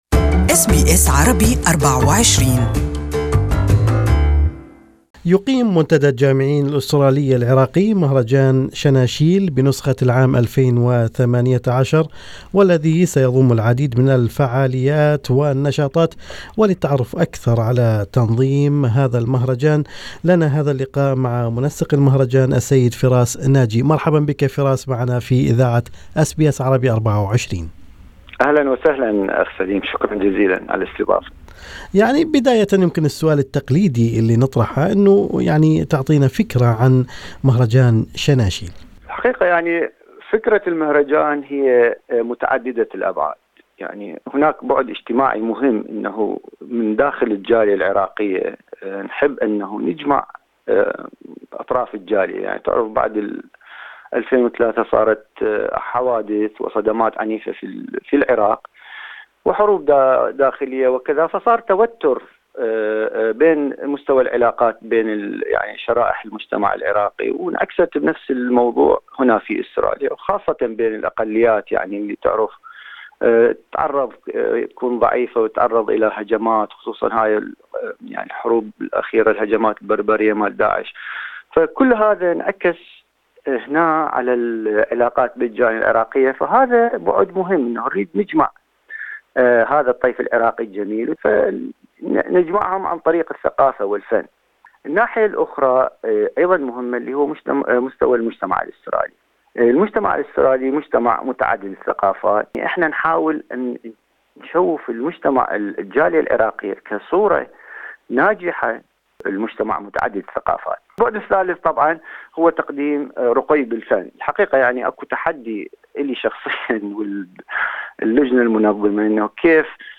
وللتعرف اكثر على تفاصيل نسخة هذا العام من شناشيل كان لمن هذا اللقاء